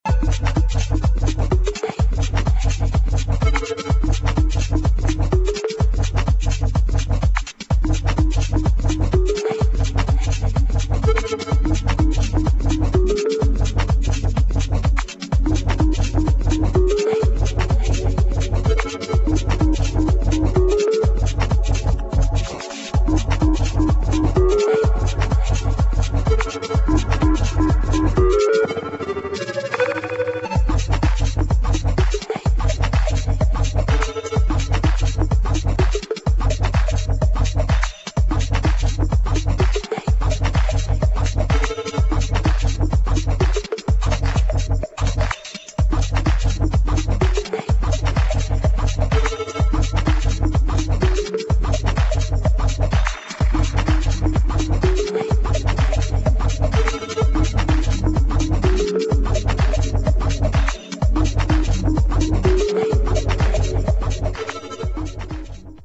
[ TECH HOUSE ]